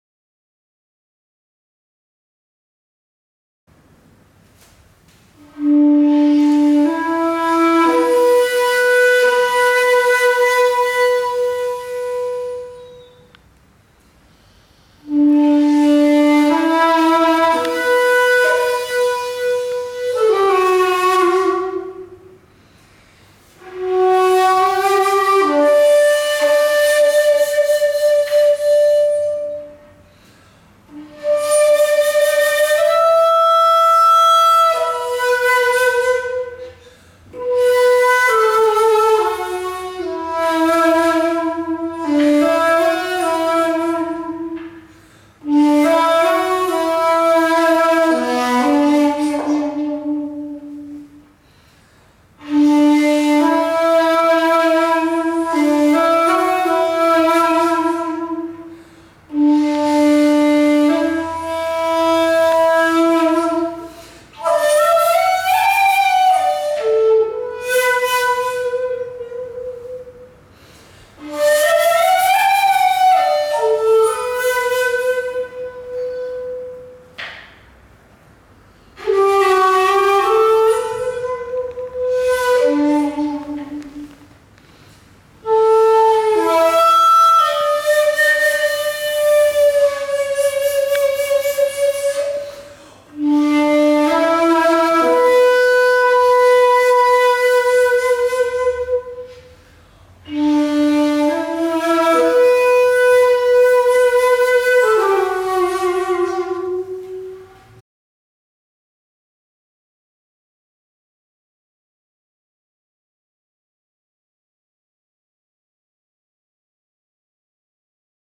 Shaku-Hatchi and Meditative Experience
Shaku-Hatchi